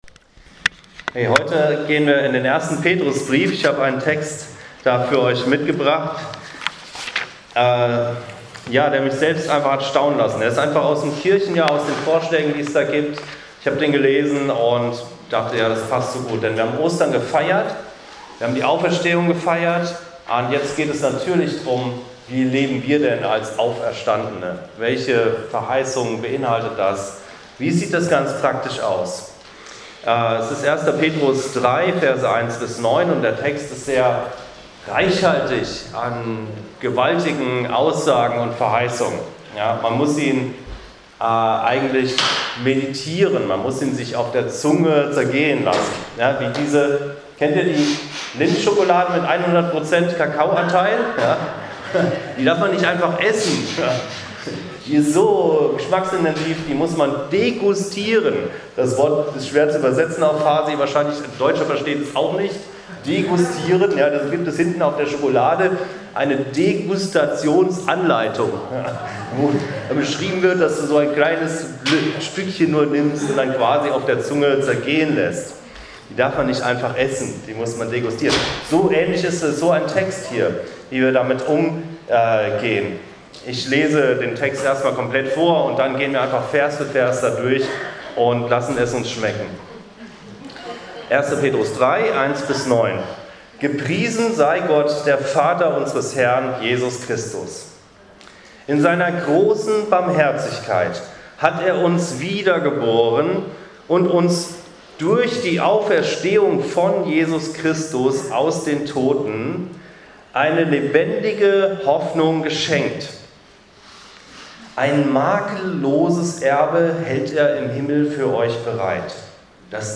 Anskar Kirche Hamburg - Predigt vom 24.04.22